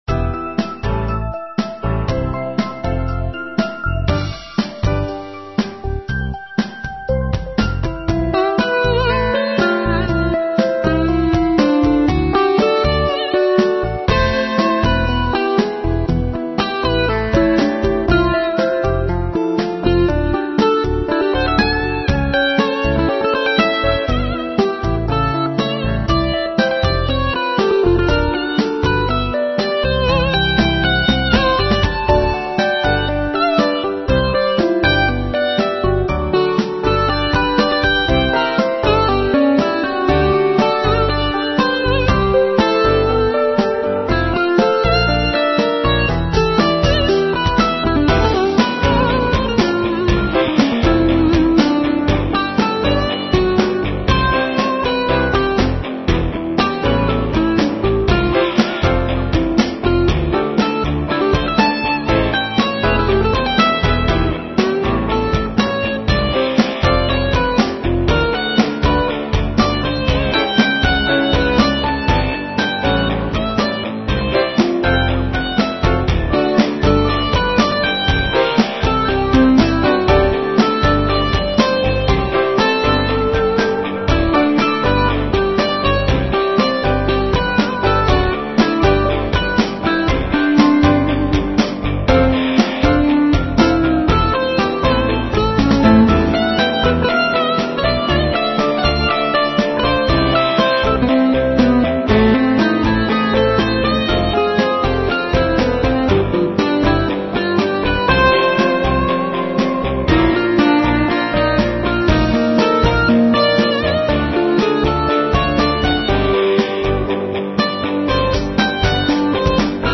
Instrumental Rock with feel of rage and tension